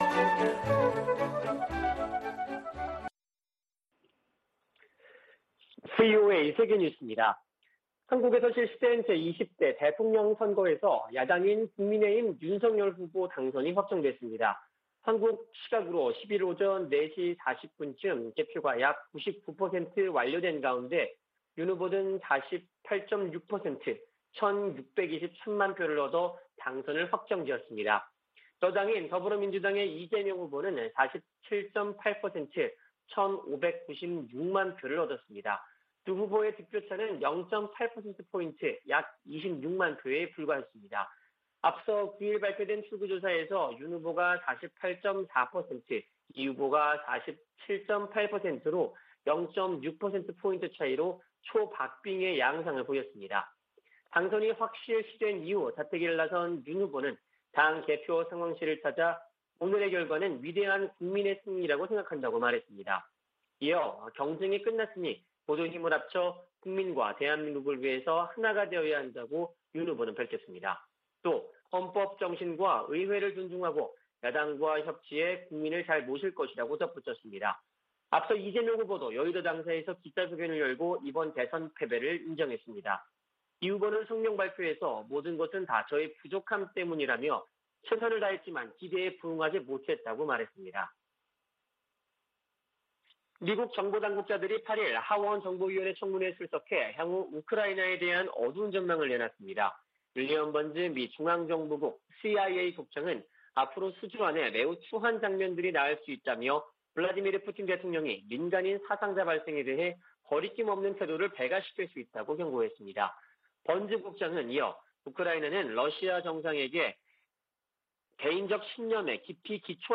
VOA 한국어 아침 뉴스 프로그램 '워싱턴 뉴스 광장' 2022년 3월 10일 방송입니다. 북한이 미국과 동맹국을 겨냥해 핵과 재래식 능력을 지속적으로 확장하고 있다고 미 국가정보국장이 평가했습니다. 북한이 신형 ICBM을 조만간 시험발사할 수 있다는 미군 고위 당국자의 전망이 나왔습니다. 한국 20대 대통령 선거가 9일 실시됐습니다.